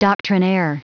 Prononciation du mot doctrinaire en anglais (fichier audio)
Prononciation du mot : doctrinaire